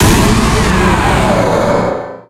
Cri de Méga-Steelix dans Pokémon Rubis Oméga et Saphir Alpha.
Cri_0208_Méga_ROSA.ogg